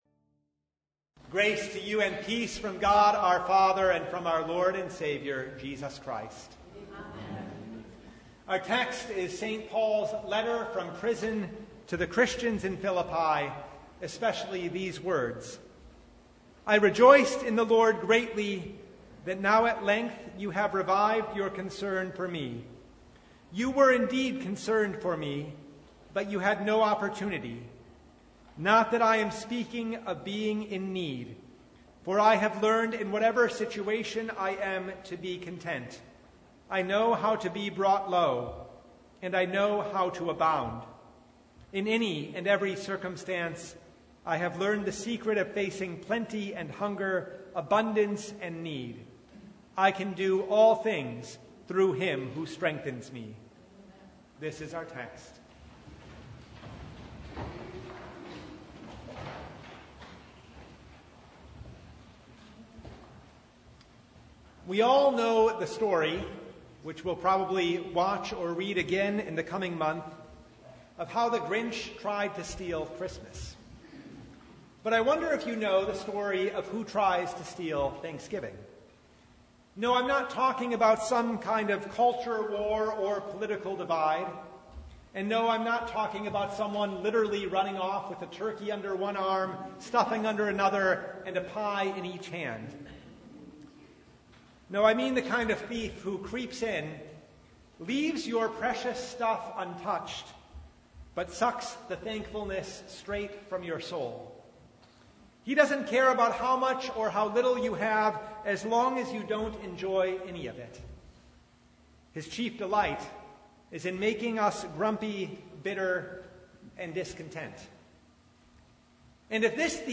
Philippians 4:6-20 Service Type: Thanksgiving Eve Comparing ourselves to others ruins all thanksgiving